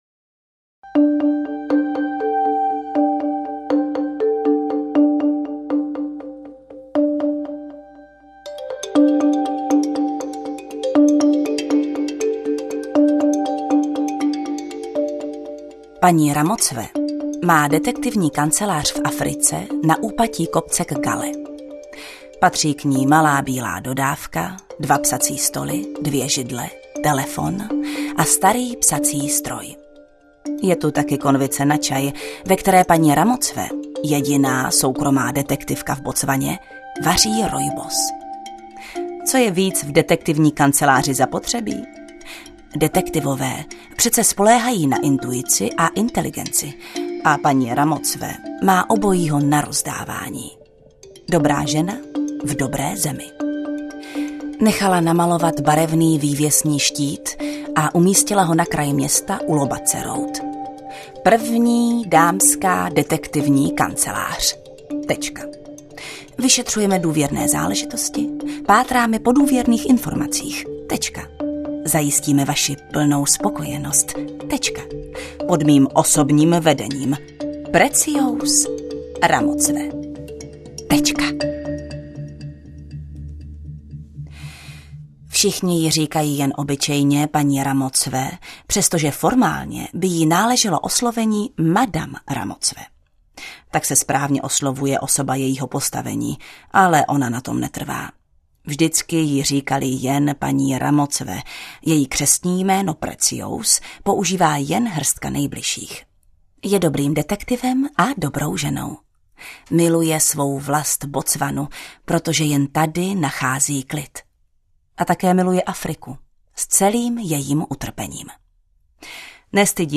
Interpret:  Jana Stryková
AudioKniha ke stažení, 10 x mp3, délka 4 hod. 18 min., velikost 354,1 MB, česky